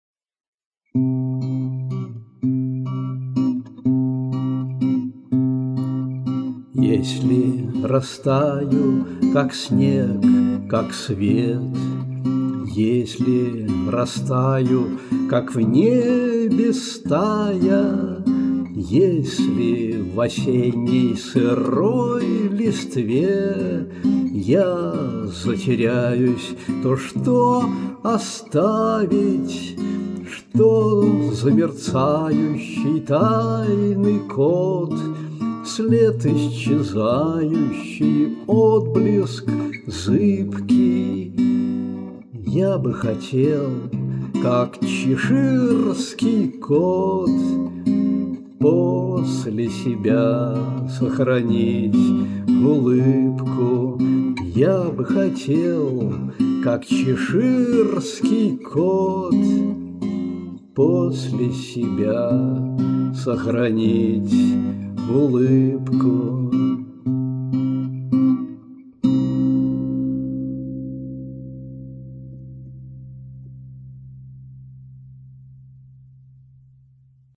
• Жанр: Романс